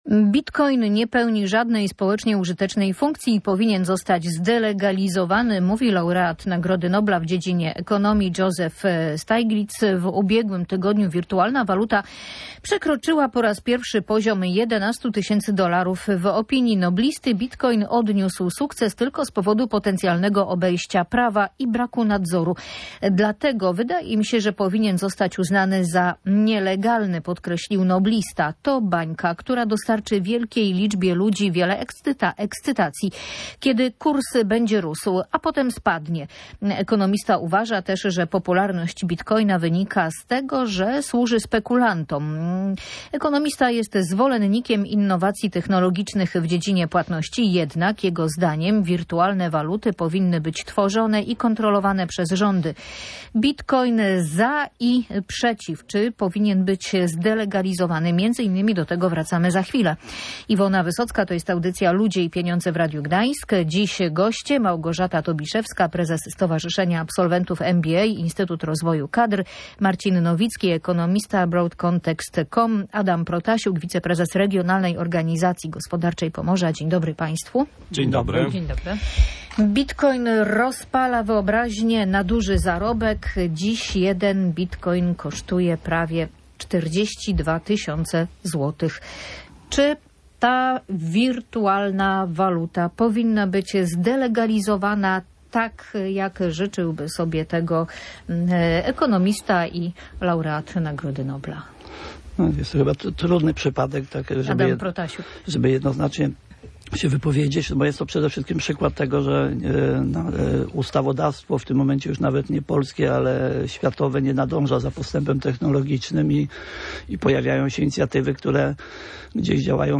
O niebezpieczeństwach związanych z popularnością wirtualnej waluty rozmawiali eksperci w audycji Ludzie i Pieniądze.